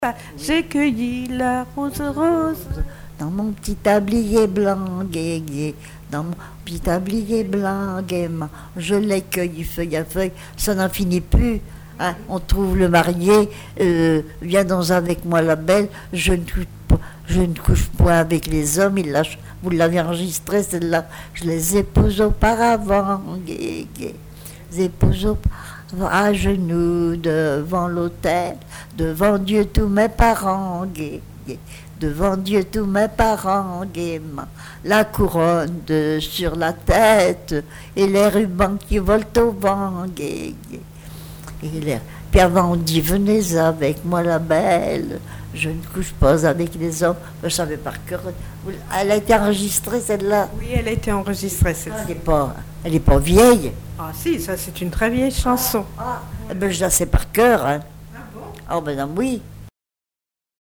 Genre laisse
Enquête Arexcpo en Vendée-C.C. Mortagne
Pièce musicale inédite